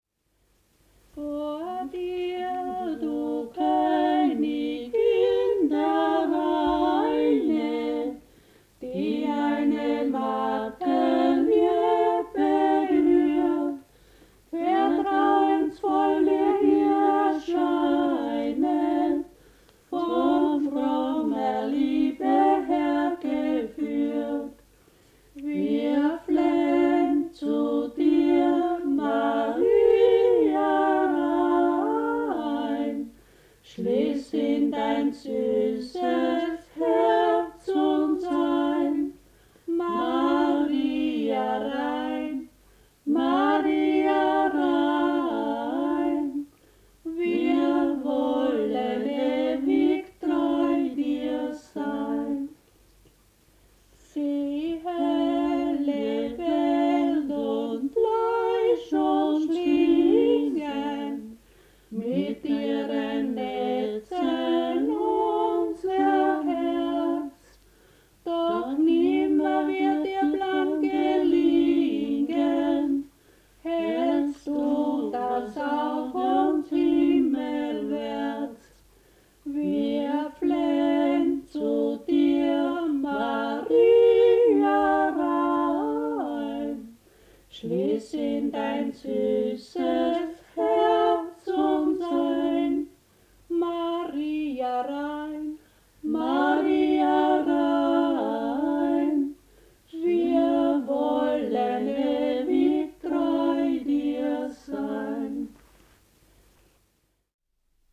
Volksmusik des Wechsels, Grenzlandschaft Steiermark-Niederösterreich.
Traditional music of the Wechsel, styrian-lower austrian border region, 100 kms south of Vienna. Volume 1 „The religious song“ sung during the farmer’s traditional two-night corpse-watch at the bier in the house of the deceased. 192 songs with text, music and incipits, 3 CDs with historical recordings, dictionary of local dialect.
Traditional music in the Styrian / Lower Austrian Wechsel-region; songs during the corpse-watch in the farmhouse of the deceased, CDs, historical recordings, dictionary of local dialect; incipits
Church music
Folk & traditional music